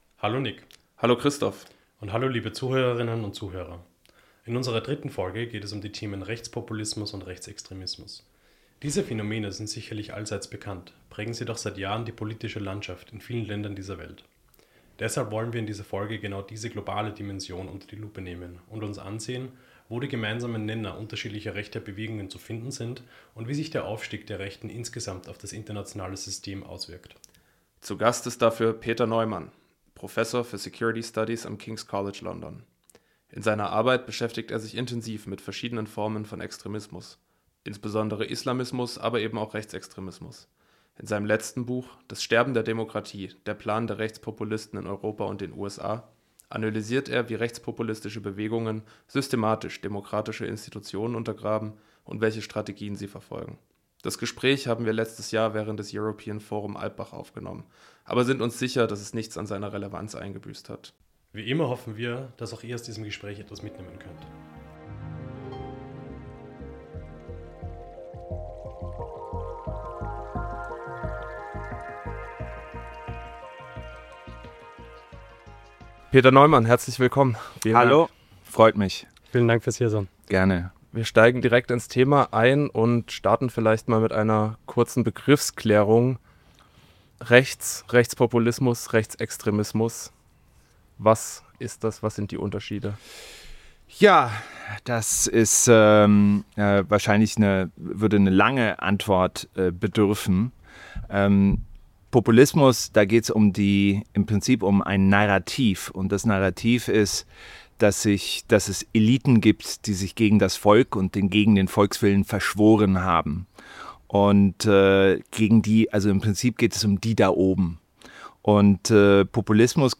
In der dritten Folge von "Aus sicherer Quelle" sprechen wir mit Peter R. Neumann, Professor für Sicherheitsstudien am Kings College London, über das globale Phänomen Rechtspopulismus.